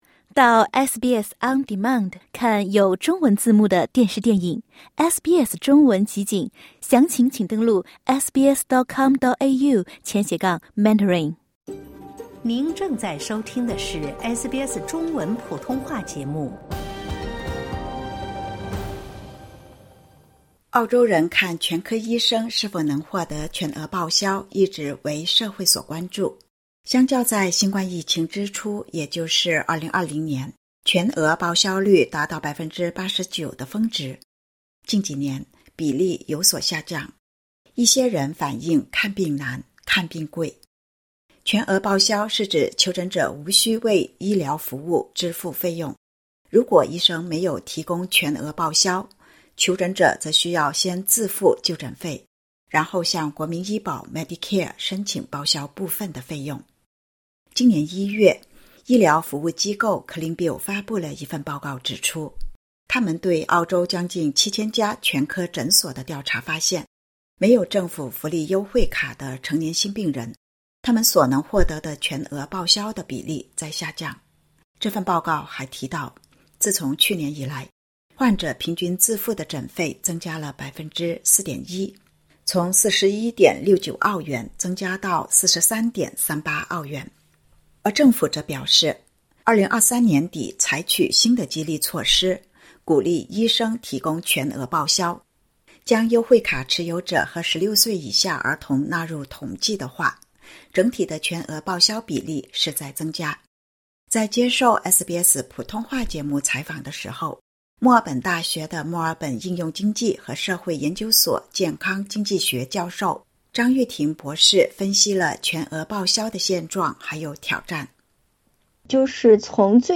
接受SBS普通话节目采访时